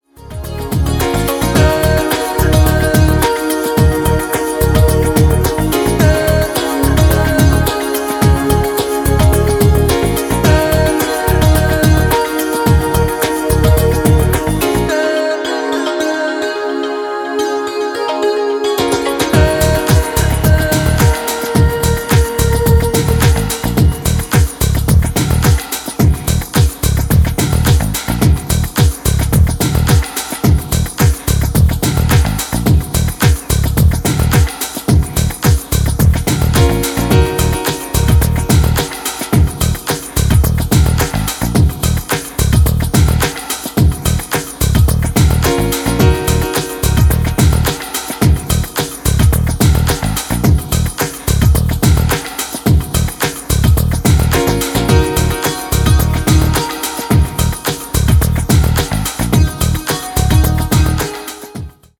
ドリーミーなブレイクビーツ